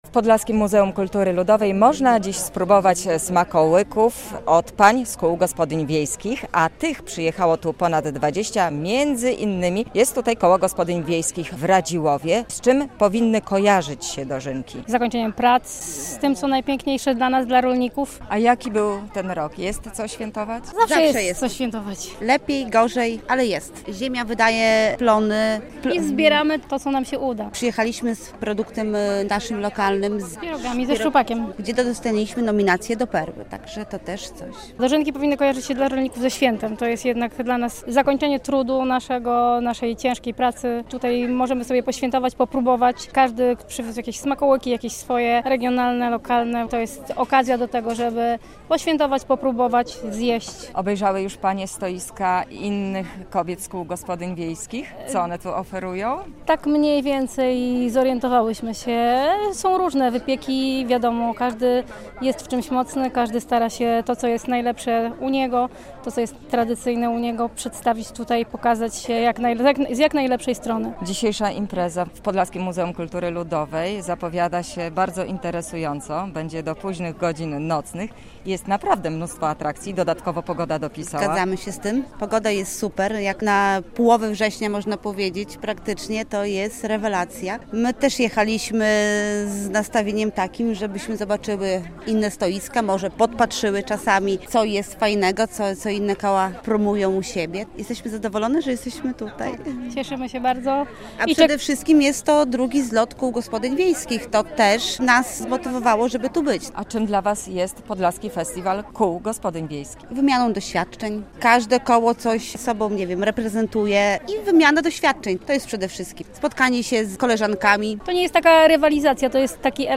W Podlaskim Muzeum Kultury Ludowej mieszkańcy regionu świętują Dożynki Wojewódzkie - relacja